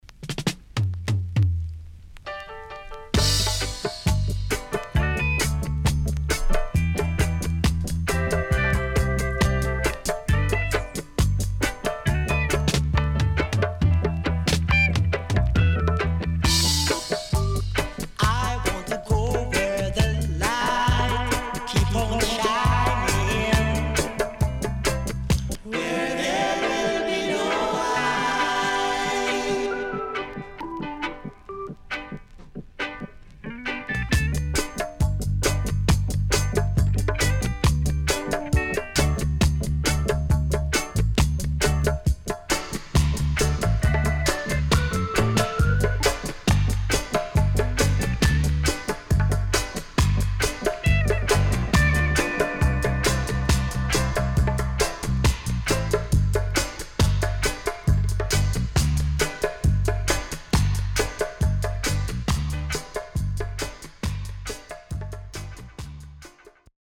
Rare.Good Vocal & Dubwise.Good Condition
SIDE A:少しチリノイズ入りますが良好です。